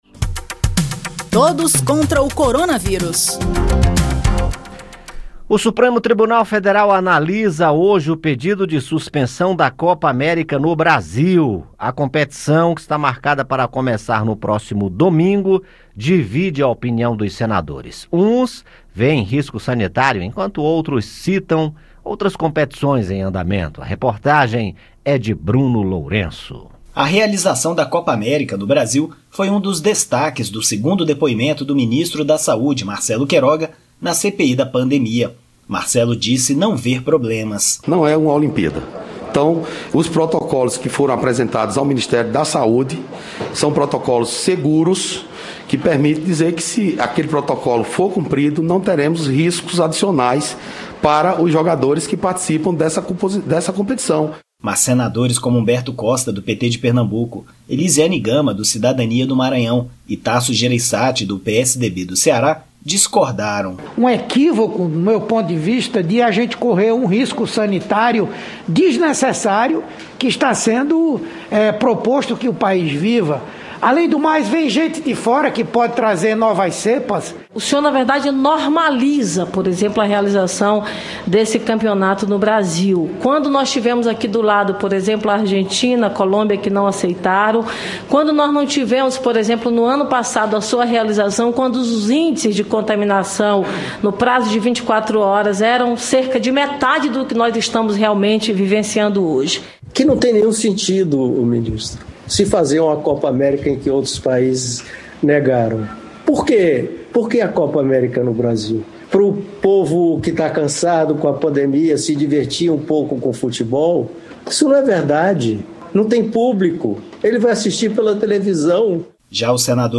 O Supremo Tribunal Federal marcou para esta quinta-feira (10) a análise de dois pedidos de suspensão da Copa América no Brasil. A realização dos jogos divide a opinião dos senadores, alguns veem risco sanitário enquanto outros citam outras competições em andamento para justificar que o risco não se fundamenta. Ouça a reportagem